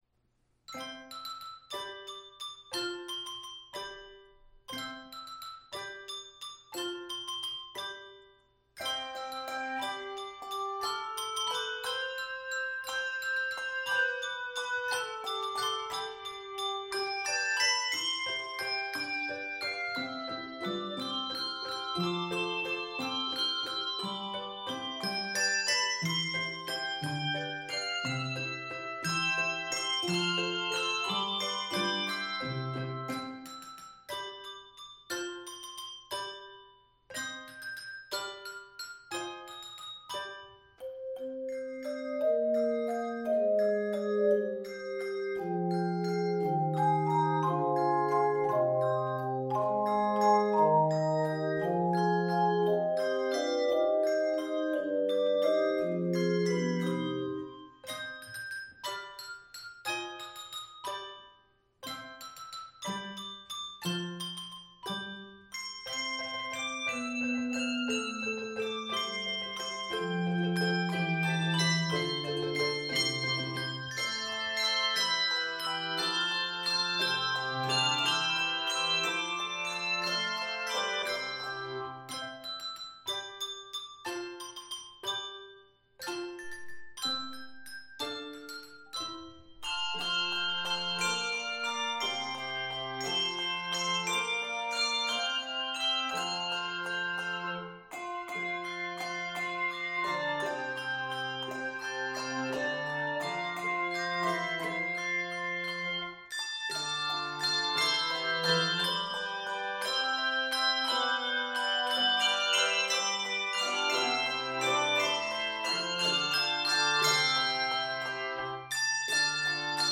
Review: Ring in the season with this joyous medley.